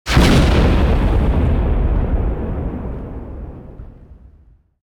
bombexplode.ogg